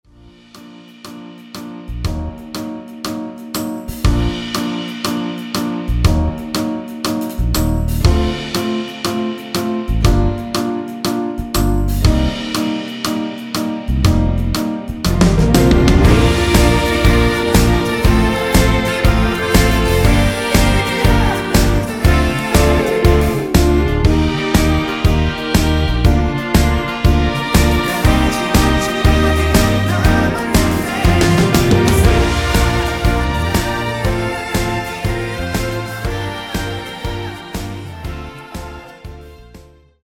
원키 코러스 포함된 MR입니다.
Bb
앞부분30초, 뒷부분30초씩 편집해서 올려 드리고 있습니다.